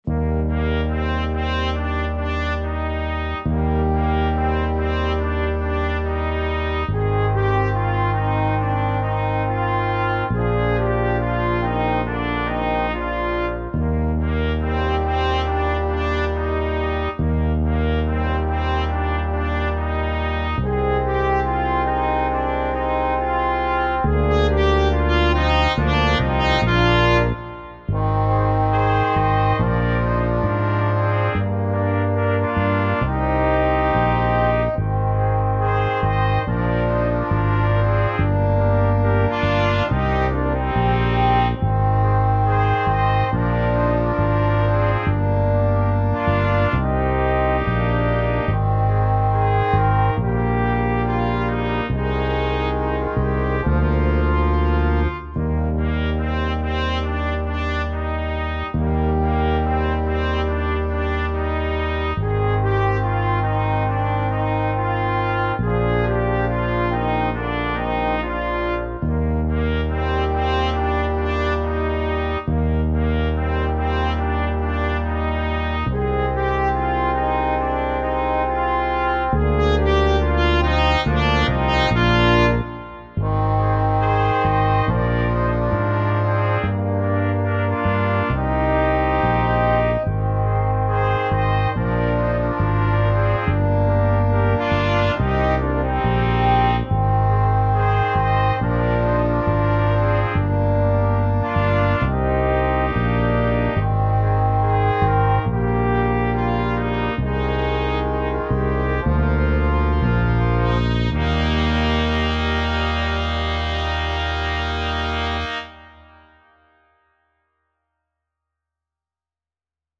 Dychové kvinteto Značky: Inštrumentalne